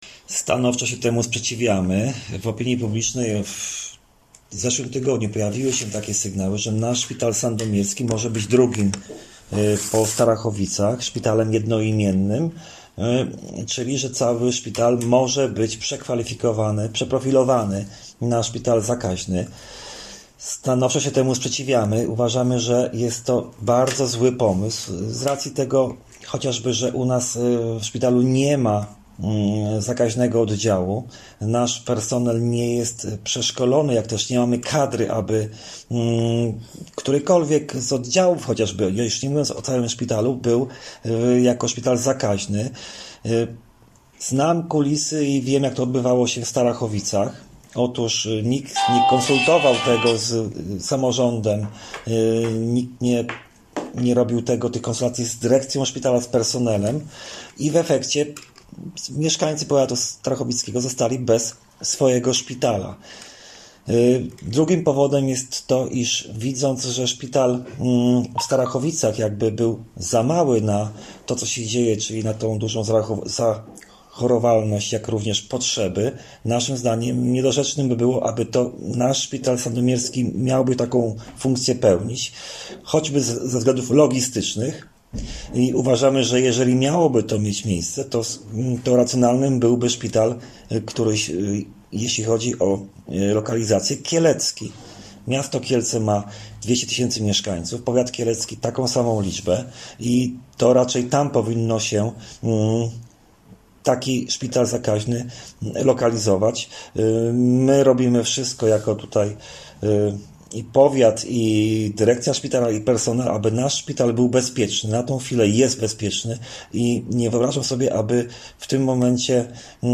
’- Nie zgadzamy się na ewentualne plany przekształcenia sandomierskiego szpitala w jednoimienny szpital zakaźny – mówi Radiu Leliwa starosta powiatu sandomierskiego Marcin Piwnik.